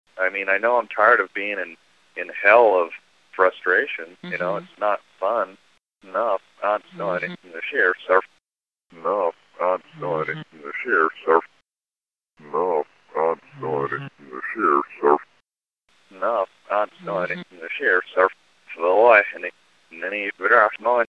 Tags: Reverse Speech Analysis Reverse Speech samples Reverse Speech clips Reverse Speech sounds Reverse Speech